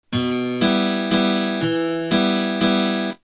Способы аккомпанимента перебором
Em (3/4)